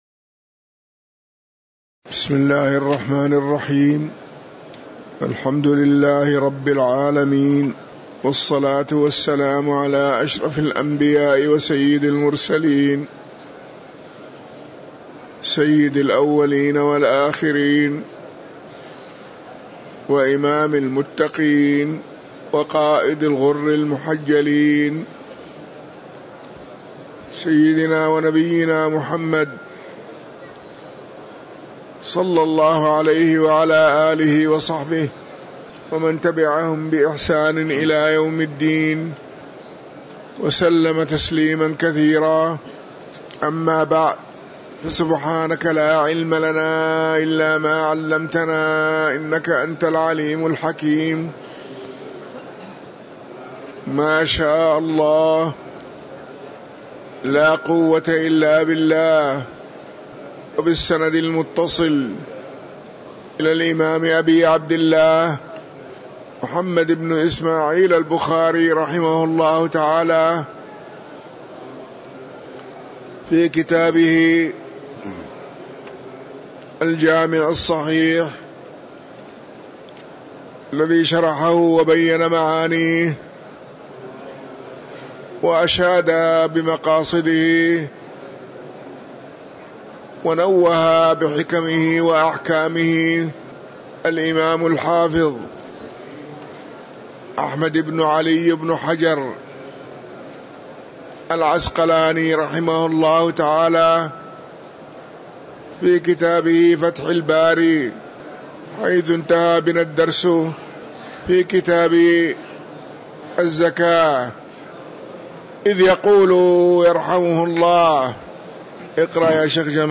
تاريخ النشر ١٣ ربيع الأول ١٤٤٤ هـ المكان: المسجد النبوي الشيخ